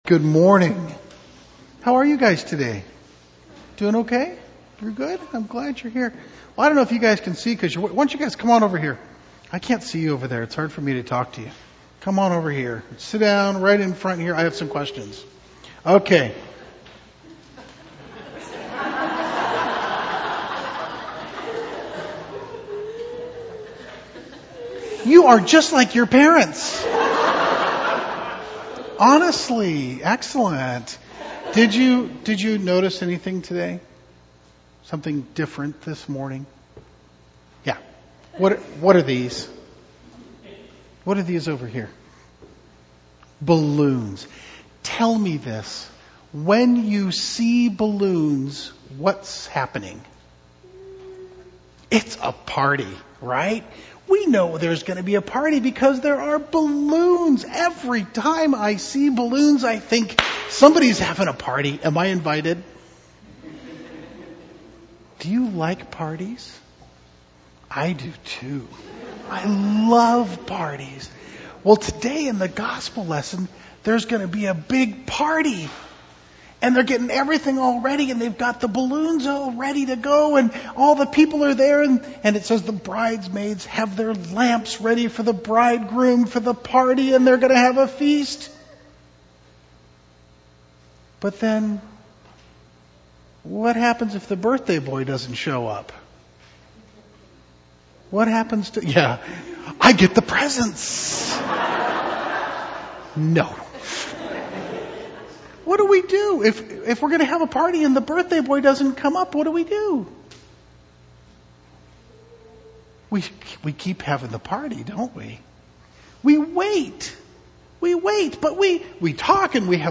LCH Children’s Conversations—Time after Pentecost 2008 (October and November)